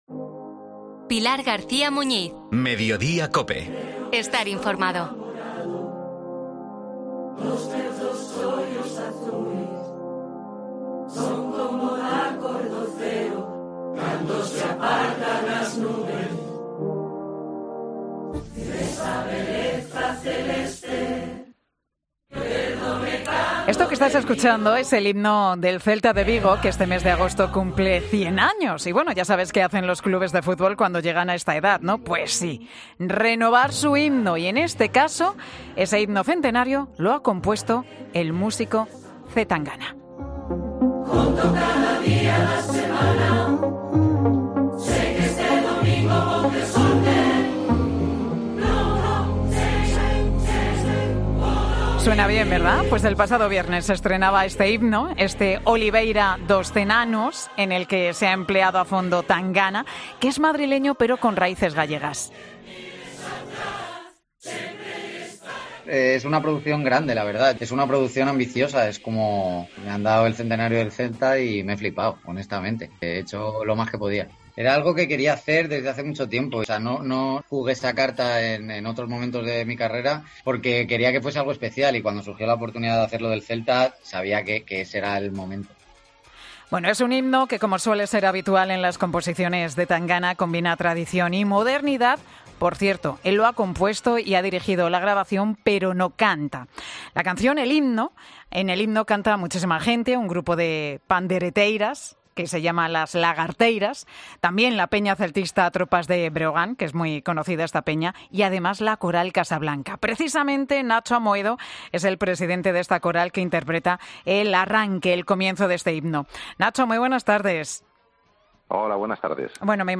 Aquí tienes la entrevista al completo de 'Mediodía COPE'.